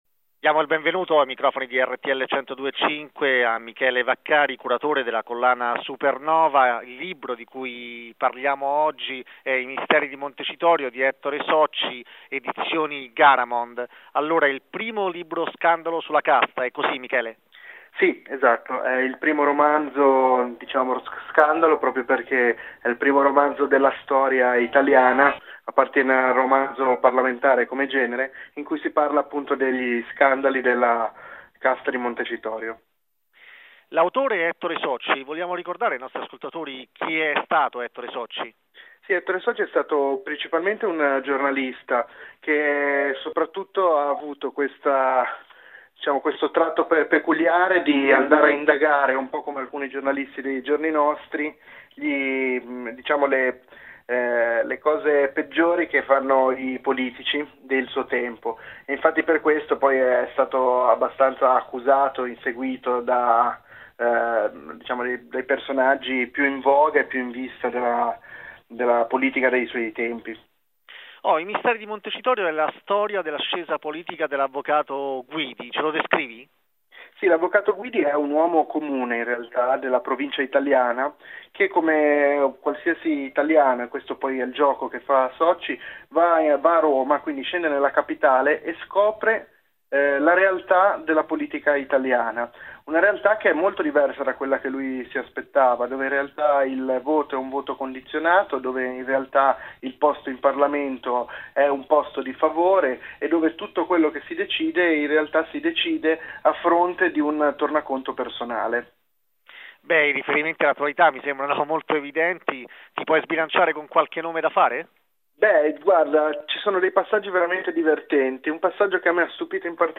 Rtl 102.5, intervista